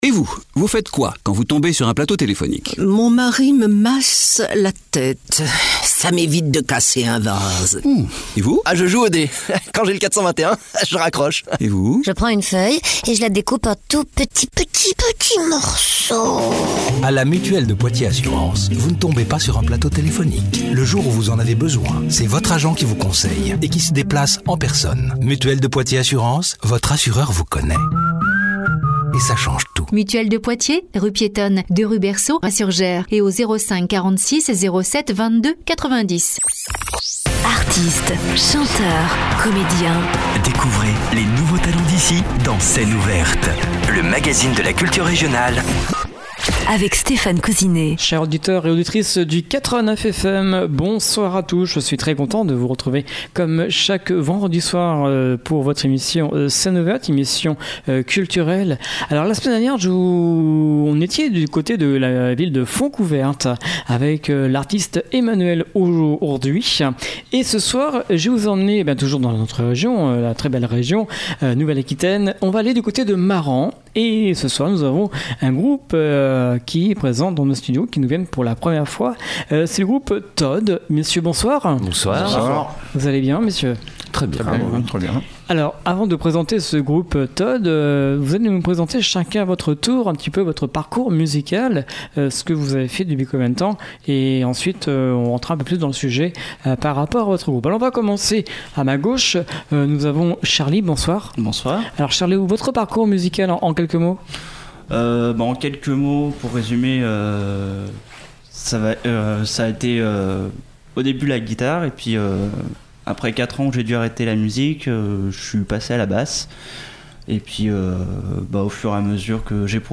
5 Membres. Influences allant du hard-rock au métal.